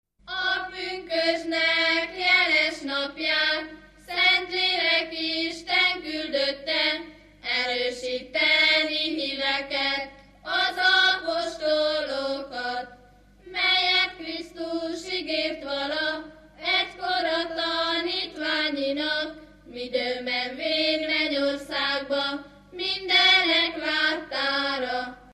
Dunántúl - Sopron vm. - Vitnyéd
Stílus: 2. Ereszkedő dúr dallamok